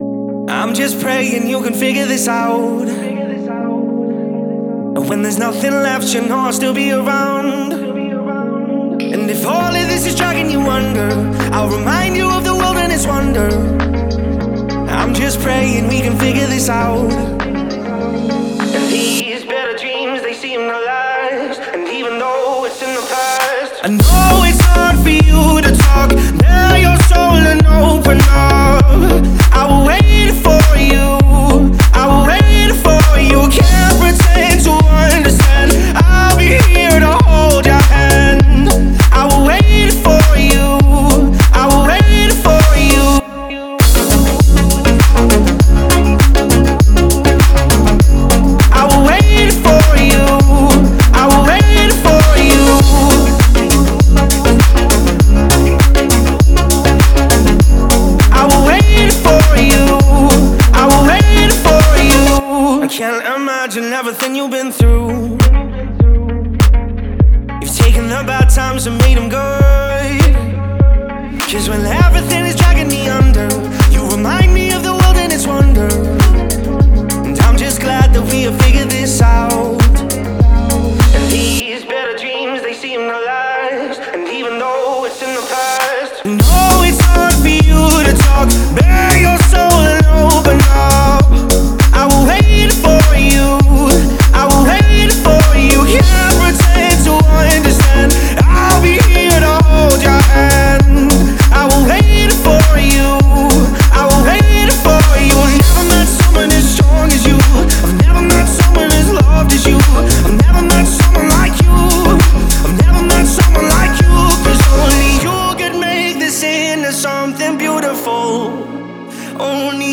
это энергичный трек в жанре EDM с элементами поп-музыки.